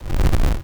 Explosion16.wav